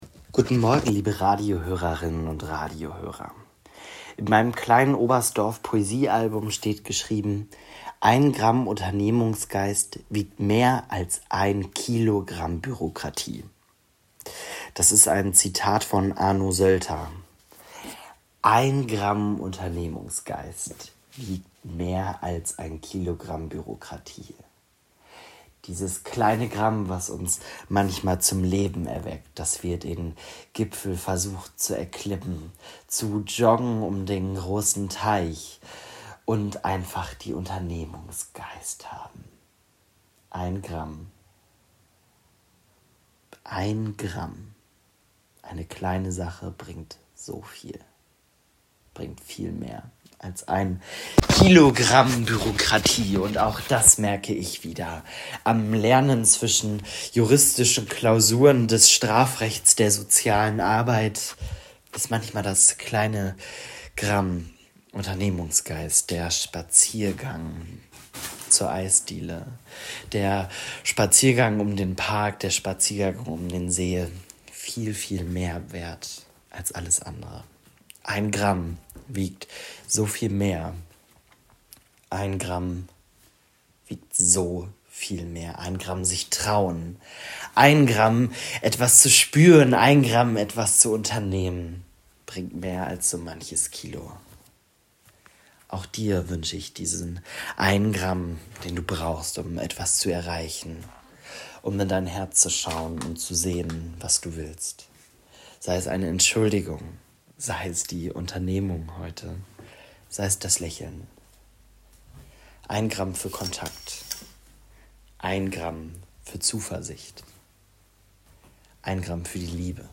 Radioandacht vom 26. Juni